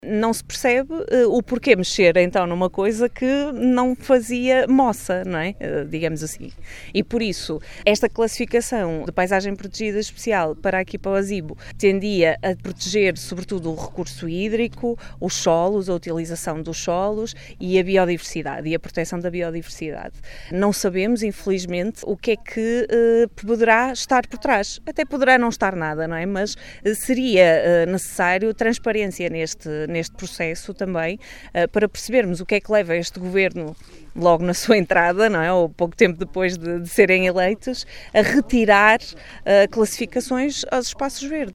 As preocupações foram explanadas no passado sábado, no âmbito de uma conferência de imprensa, na Praia da Ribeira, na albufeira do Azibo, no âmbito do Roteiro do Ambiente, que promoveu pelo distrito de Bragança.